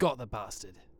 Barklines Combat VA